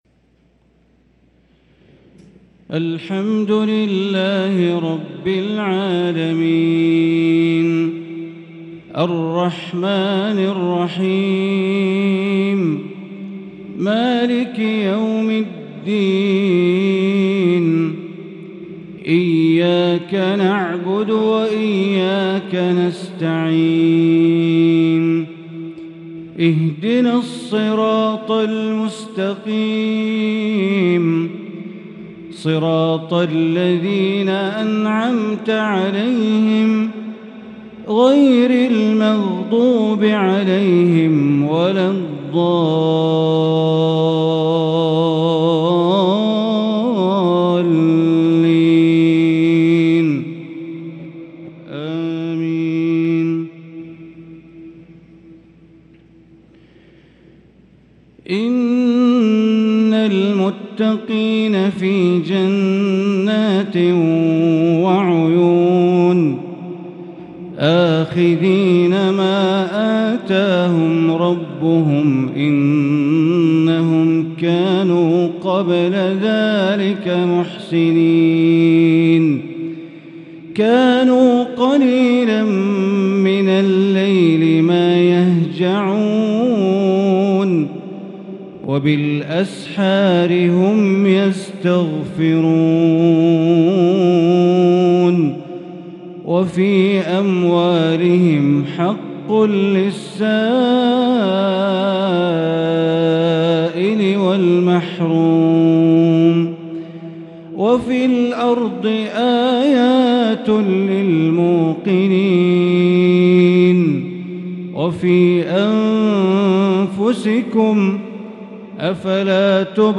صلاة العشاء 3 شوال 1433هـ من سورتي الذاريات و الطور | Isha prayer from surat ad-Dhariyat & at-Tur 4-5-2022 > 1443 🕋 > الفروض - تلاوات الحرمين